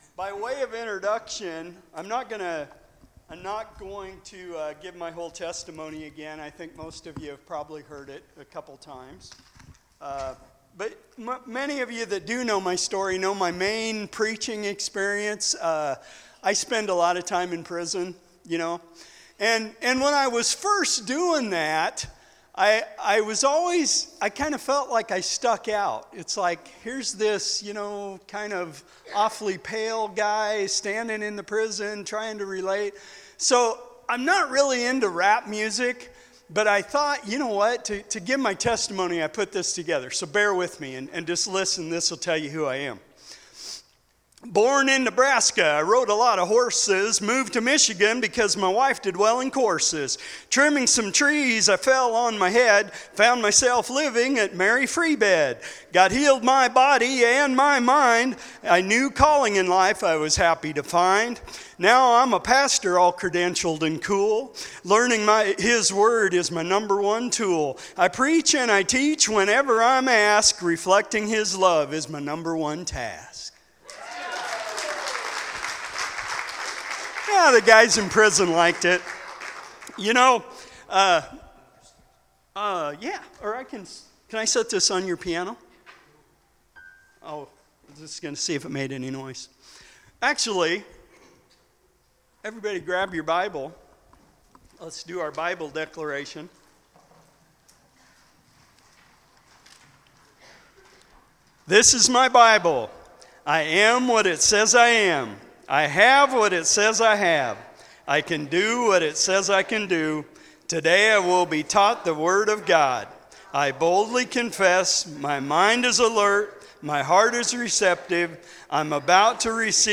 Sermon-6-02-24.mp3